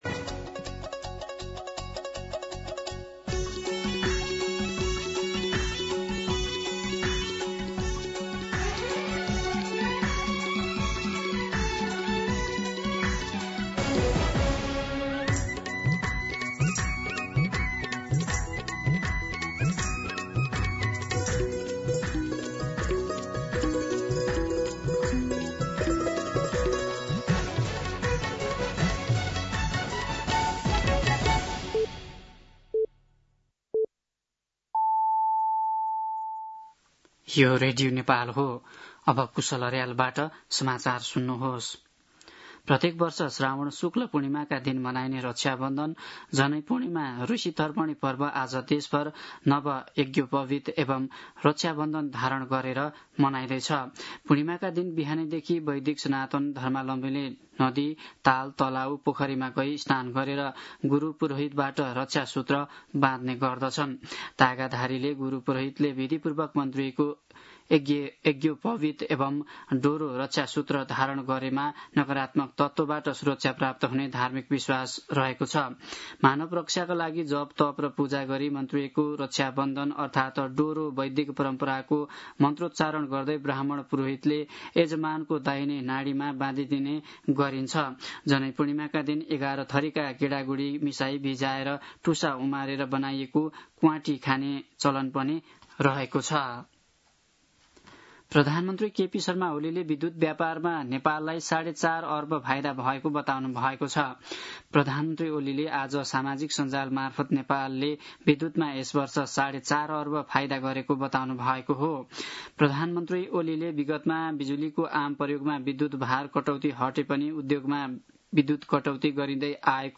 दिउँसो ४ बजेको नेपाली समाचार : २४ साउन , २०८२
4pm-News-24.mp3